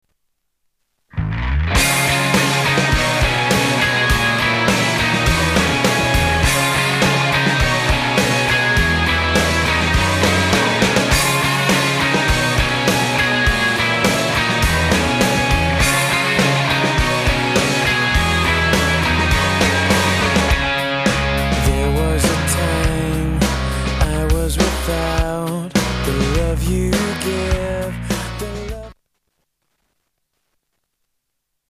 STYLE: Rock
It does sound sort of '80s retro...